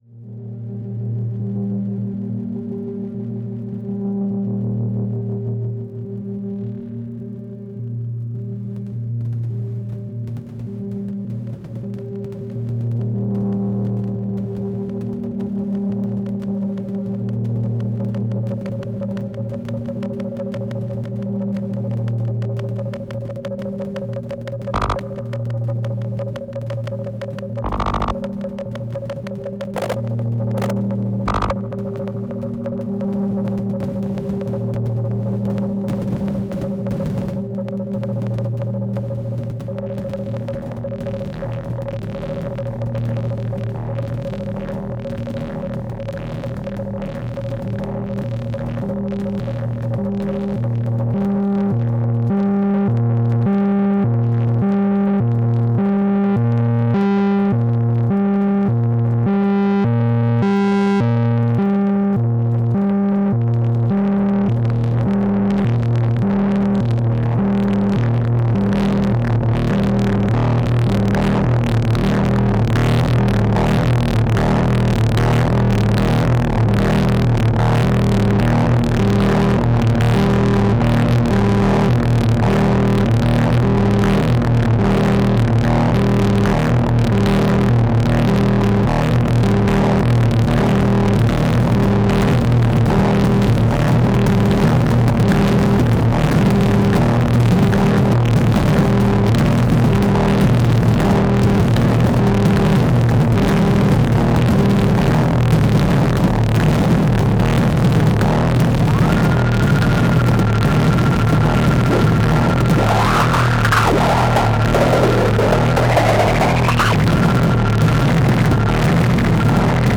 Дроны.. нет, не так - ДРОНЫ!!! мама дорогая, что ж из него можно вытащить, когда освоишься прибор нереально монстрический, отрабатывает с лихвой каждую уплаченную копейку Пребываю в диком восторге, аж дрожу. Вложения pulsar drone.mp3 pulsar drone.mp3 9,6 MB · Просмотры: 1.553